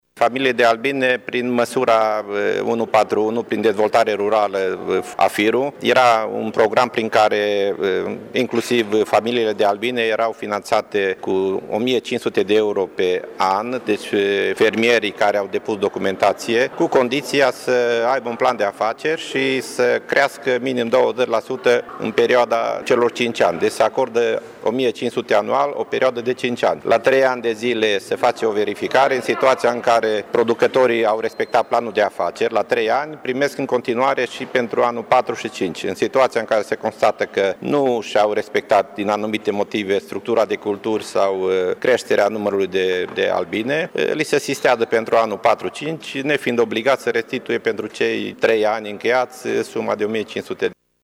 Şeful Direcţiei, Ioan Rus, a explicat că această creştere se datorează implementării măsurii prin care crescătorii pot beneficia pe o perioadă de 5 ani de o subvenţie de 1.500 de euro pe an: